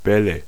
Ääntäminen
IPA : /bɔːlz/